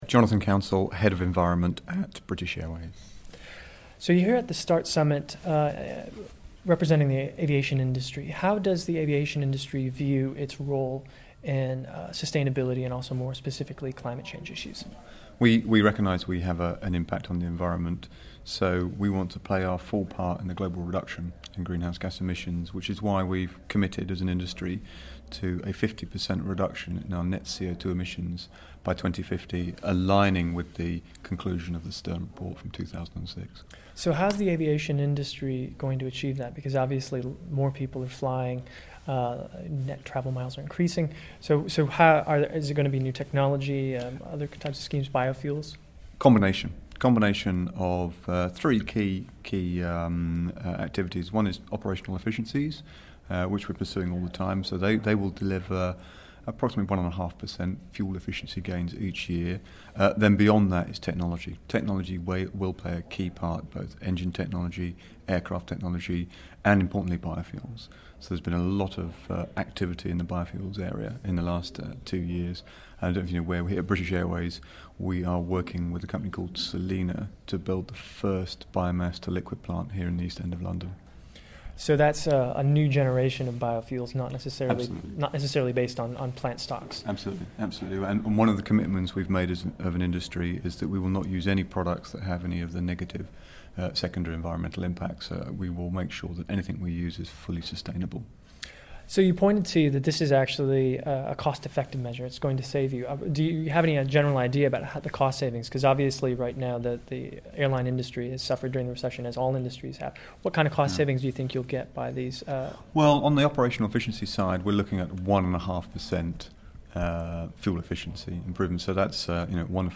IBM Start Day 3 - Interview